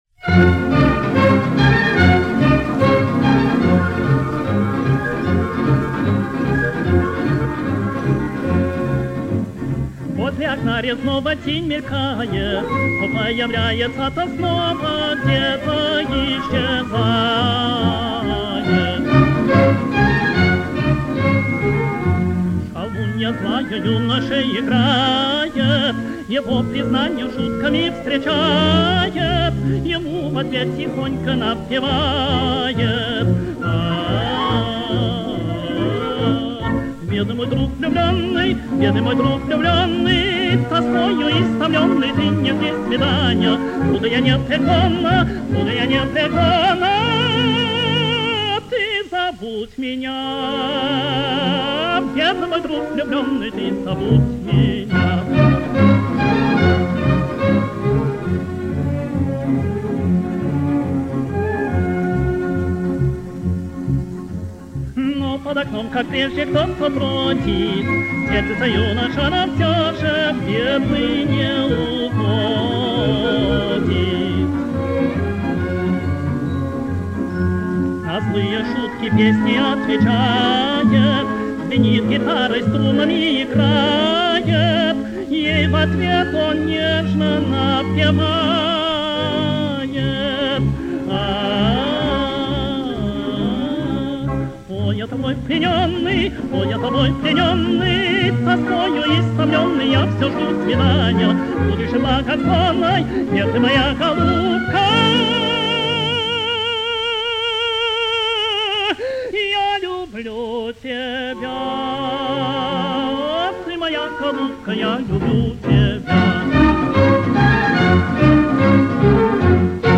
С патефонной пластинки, качество домашнее.